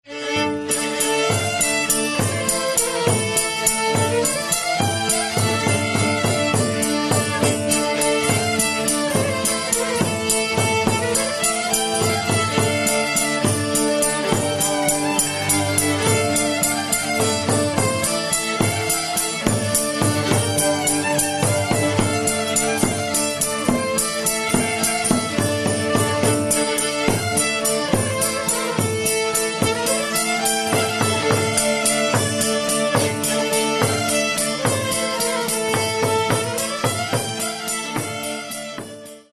Каталог -> Народная -> Ансамбли народной музыки